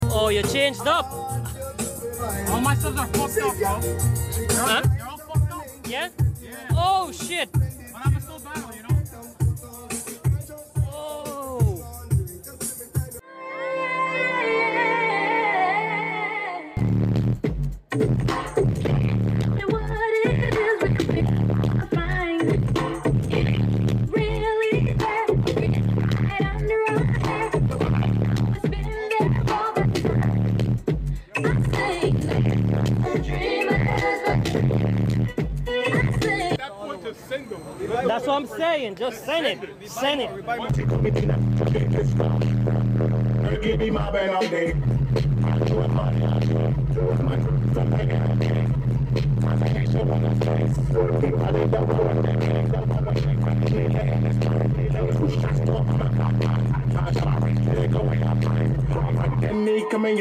Car Audio Middleweight Street Battles